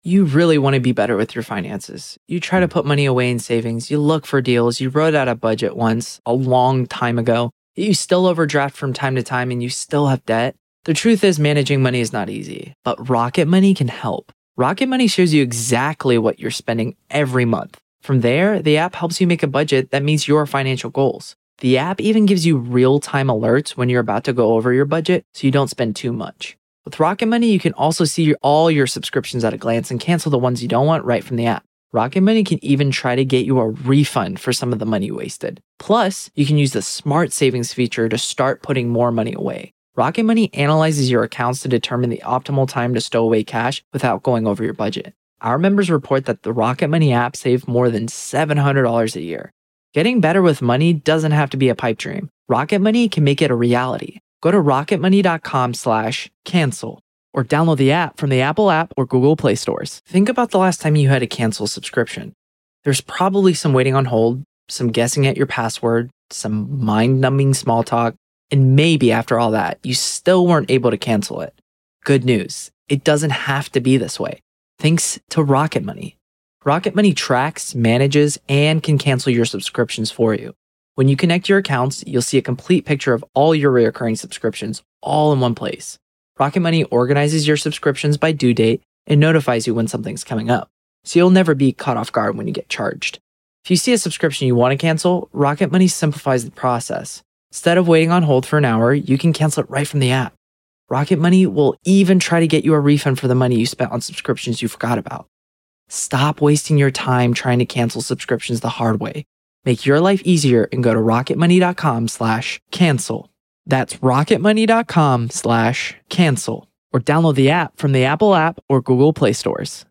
The latest Spanish news headlines in English: April 30th 2025